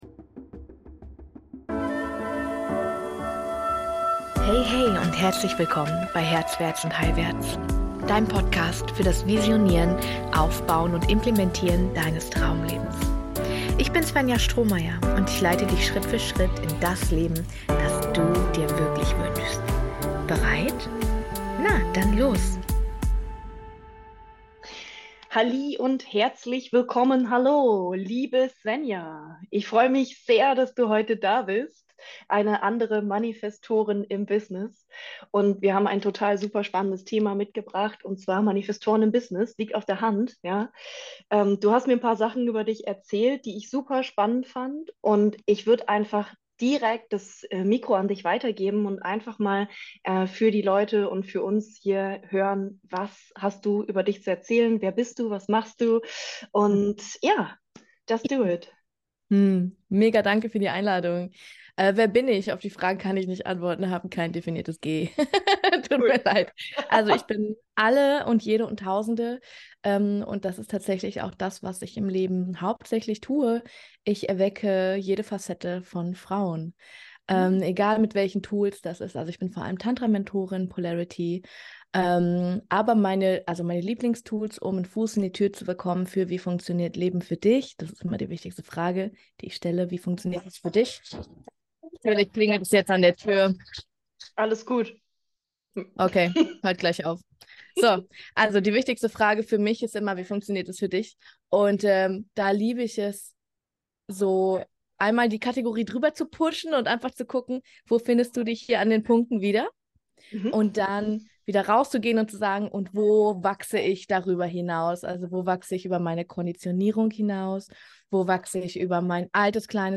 Wir sprechen von Manifestorin zu Manifestorin über Business, Polarität, Trauma, Partnerschaft und Konditionieru...